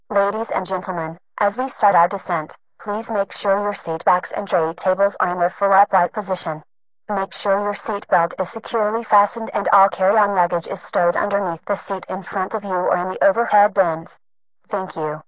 fa_descent.wav